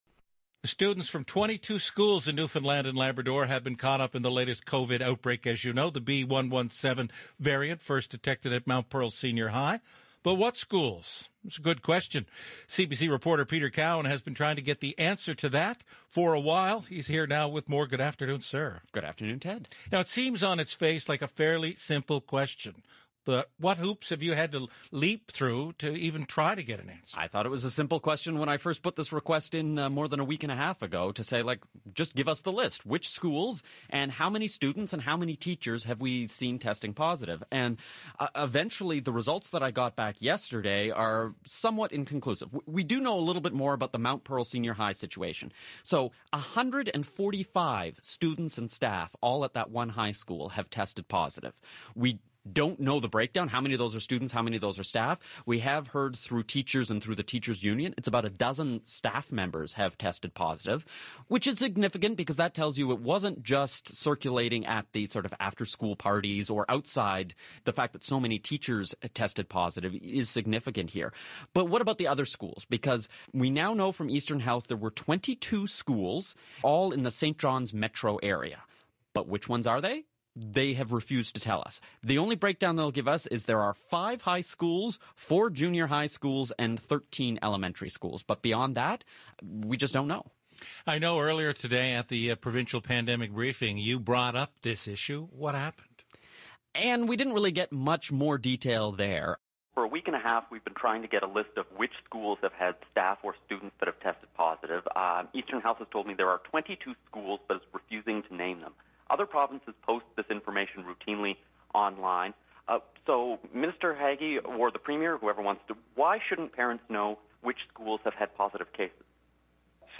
Media Interview - CBC On the Go - Feb 24, 2021